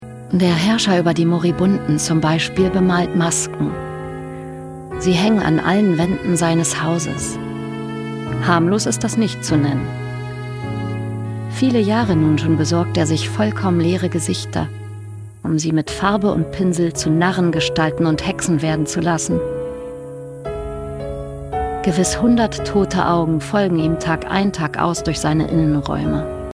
sehr variabel
Mittel plus (35-65)
Norddeutsch
Netto Radiospot Werbung
Commercial (Werbung)